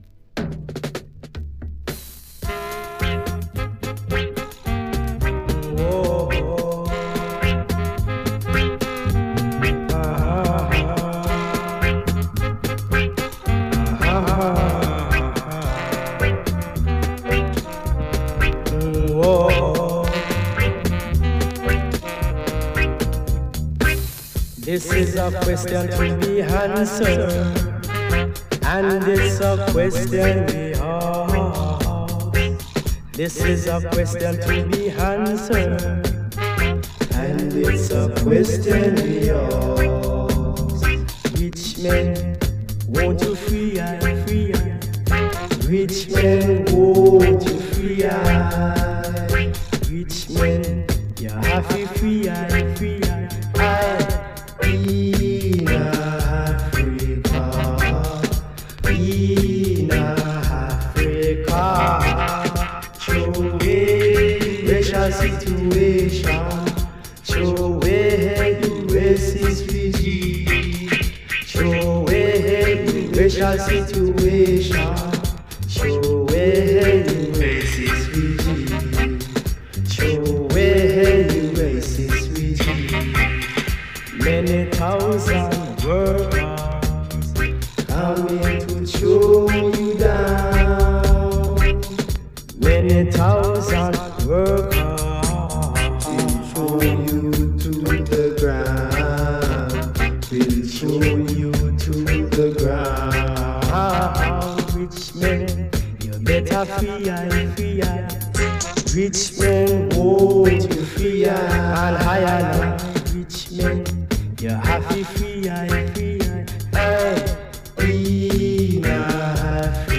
VINYL ONLY